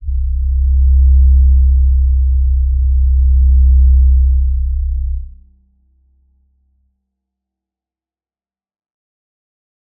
G_Crystal-C2-pp.wav